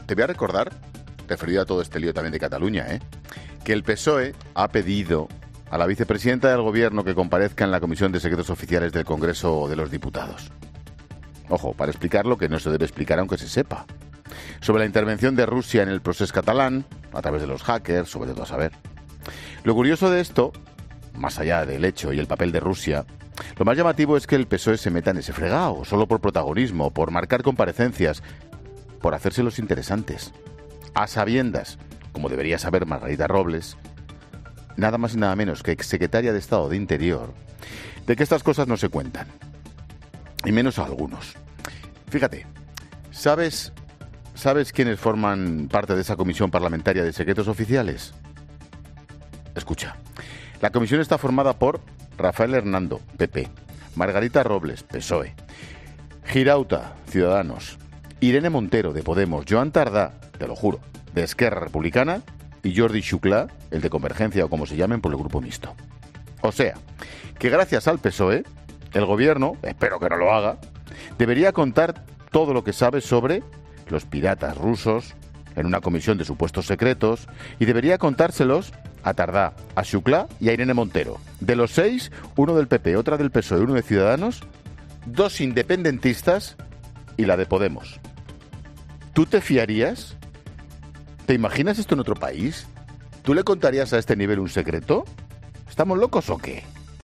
AUDIO: El comentario de Ángel Expósito sobre la propuesta del PSOE.
Monólogo de Expósito Expósito a las 17h: ¿Se debería contar todo sobre los hackers rusos en una comisión de supuestos secretos?